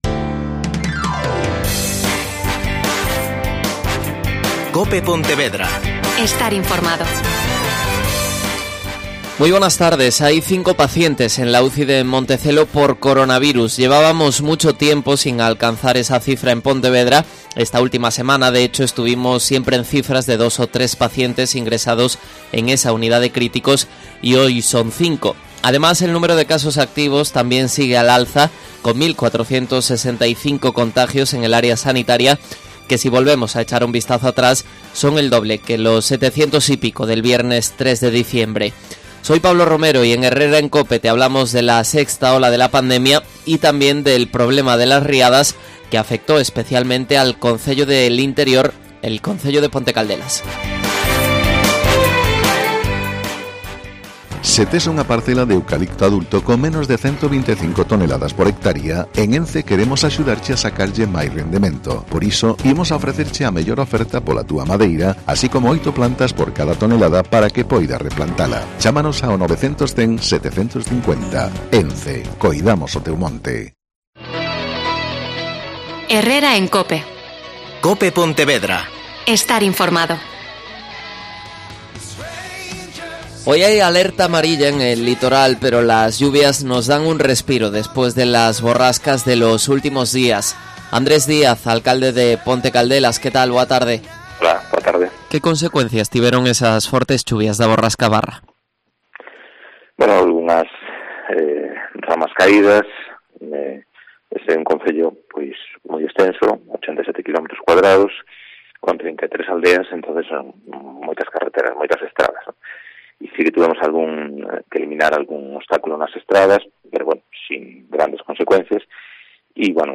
AUDIO: Andrés Díaz. Alcalde de Ponte Caldelas.